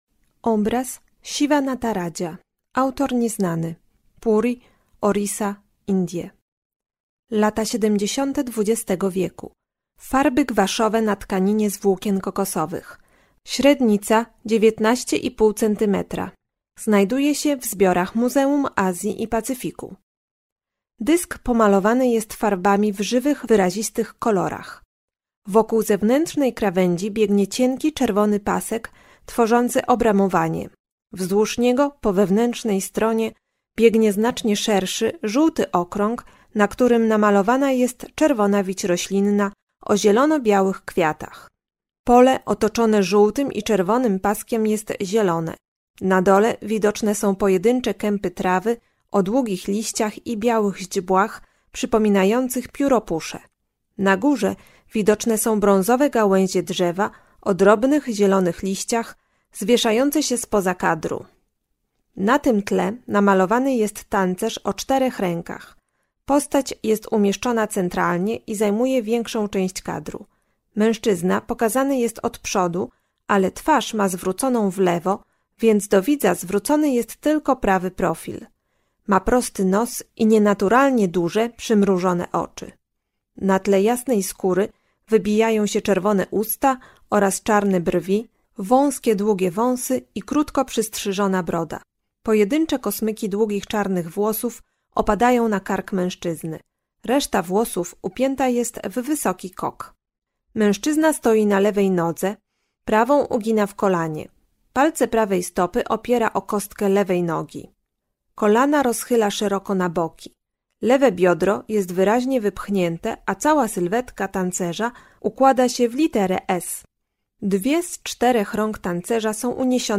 Audiodeskrypcje